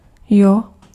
Ääntäminen
US : IPA : [jɛ.ə] US : IPA : [jæ]